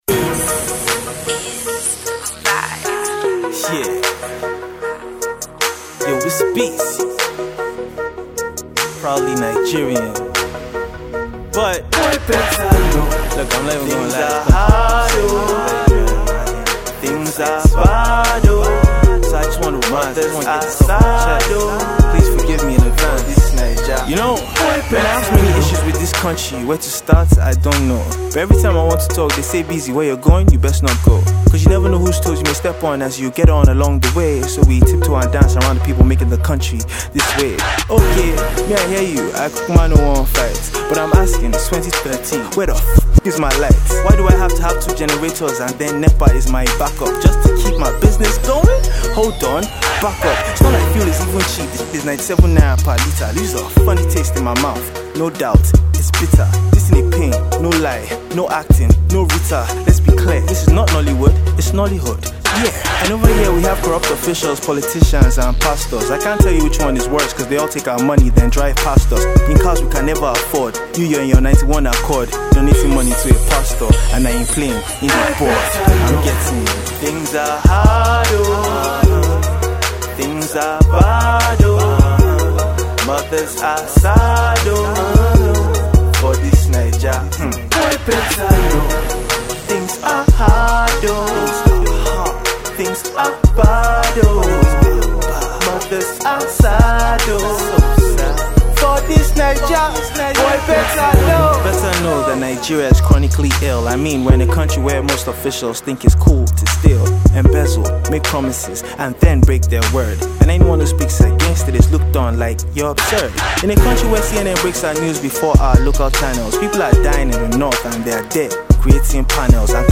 gets back on his spoken word flow for this week’s tune
rhymes about the state of Nigeria right now